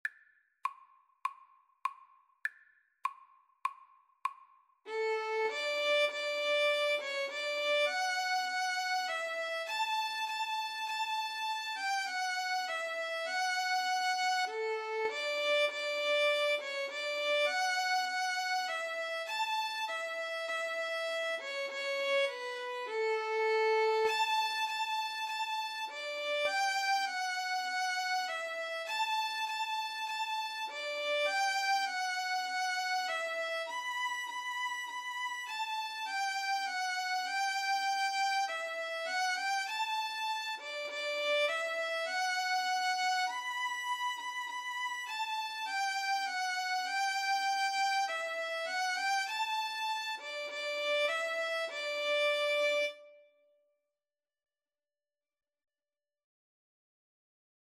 Free Sheet music for Violin-Viola Duet
D major (Sounding Pitch) (View more D major Music for Violin-Viola Duet )
Classical (View more Classical Violin-Viola Duet Music)